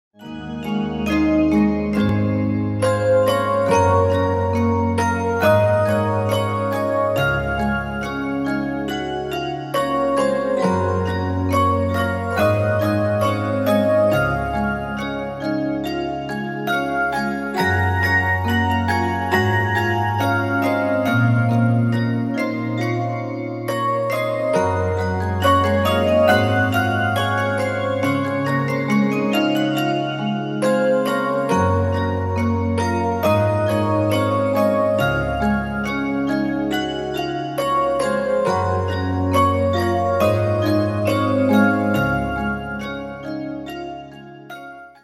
せつなさと愛しさがあふれるハートウォーミングなサウンドで、ひとときの安らぎと小さな春をお届けします―。